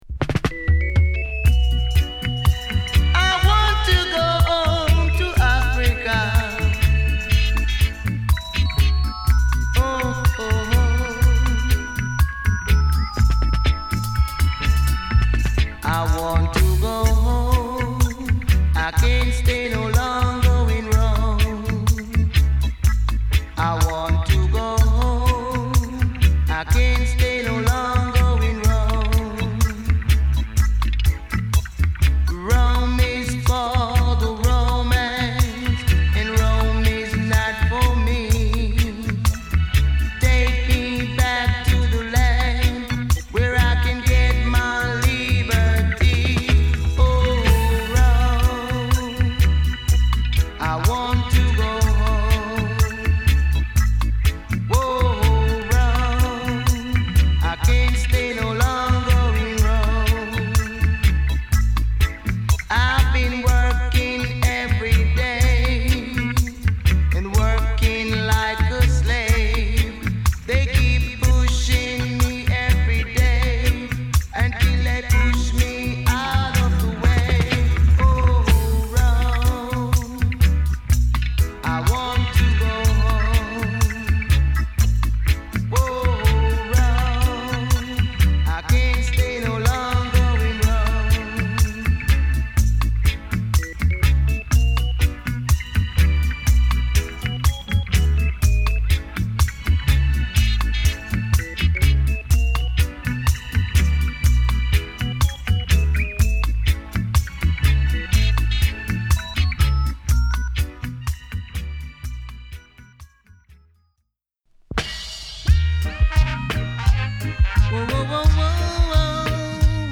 ゆるいほんわかチューンを多数収録